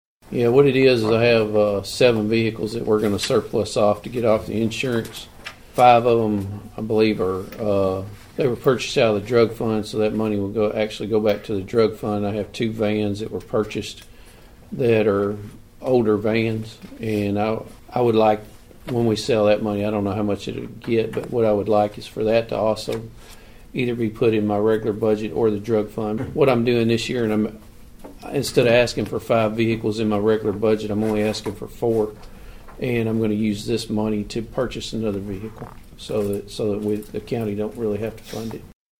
Obion County Sheriff Karl Jackson addressed the Budget Committee on Tuesday.